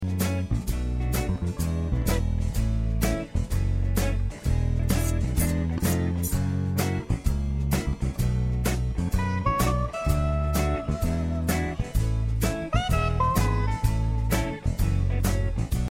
Listen to a sample of this instrumental song.